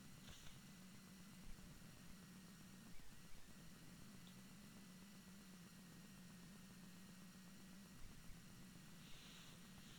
Move the modem farther away, and the glitch starts to fade:
▶ Sound of MEMS 30cm away from the modem, stereo
7_mems_mono_16bit_far-modem.wav